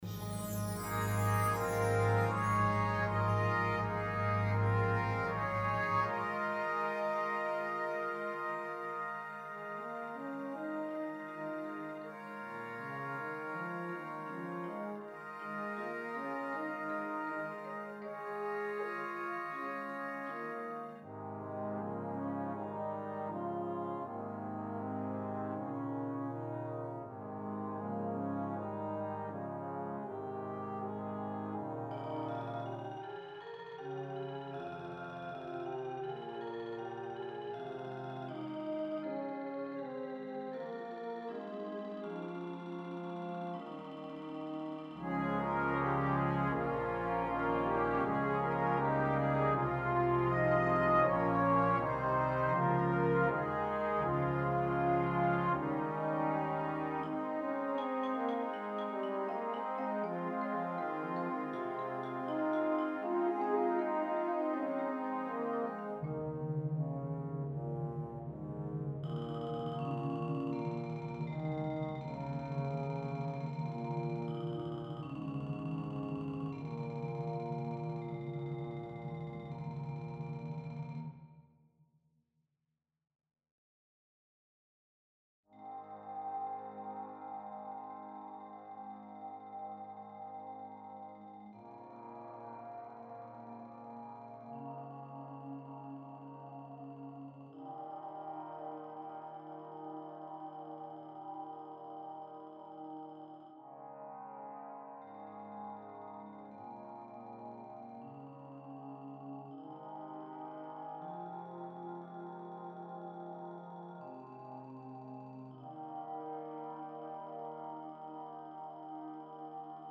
Besetzung: Euphonium, Marimba Duet & Brass Band